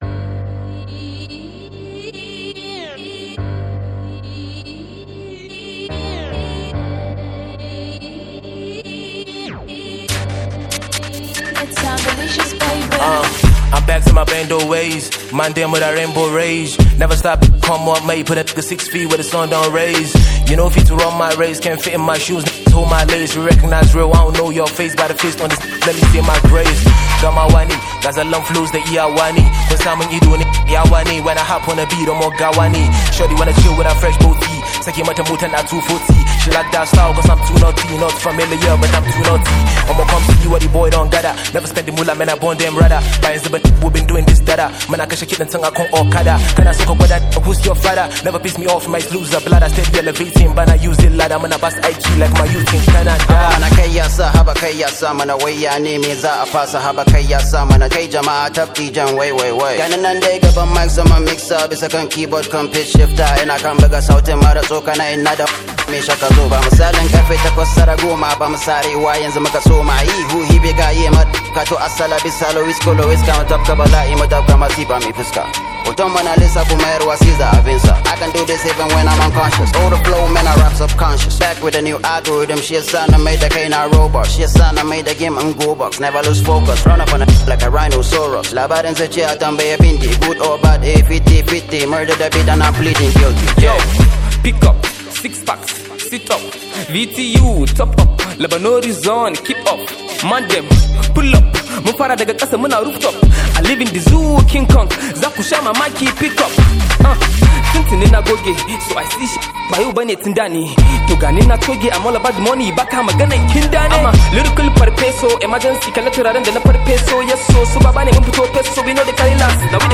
Hausa Hip Hop